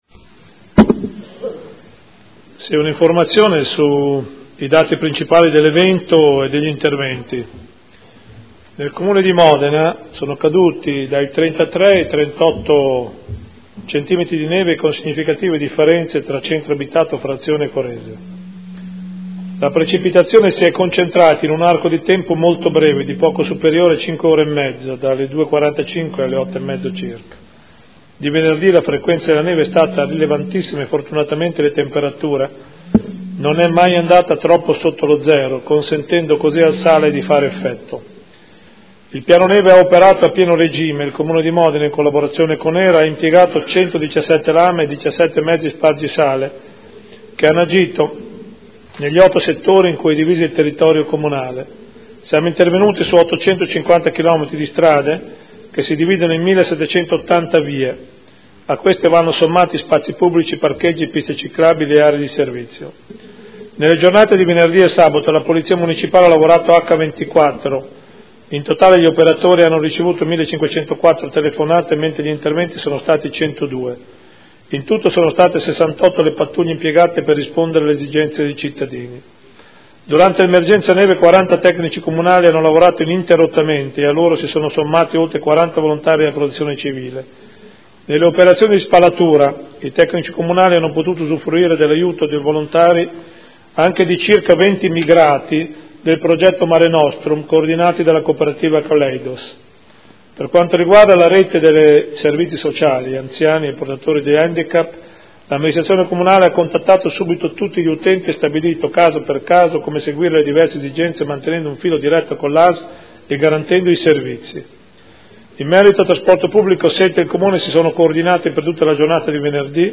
Seduta del 09/02/2015. Comunicazioni del Sindaco sul piano neve